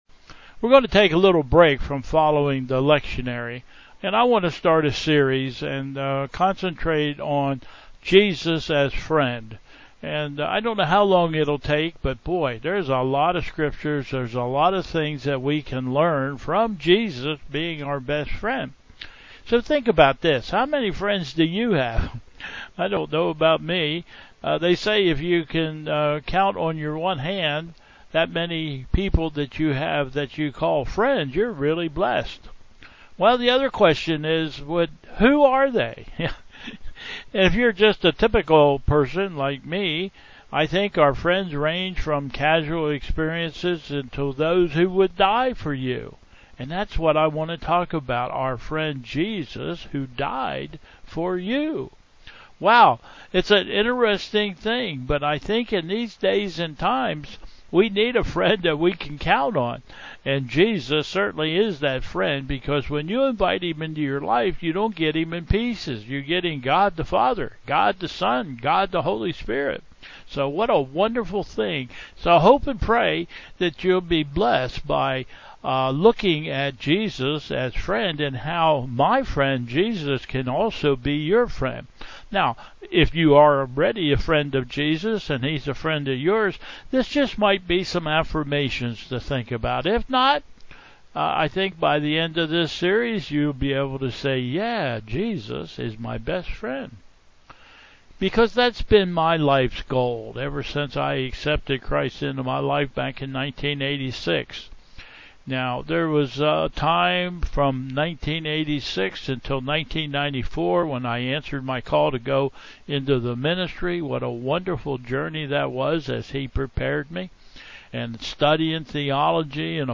BUMCTLH 10/12/25 Service
Announcements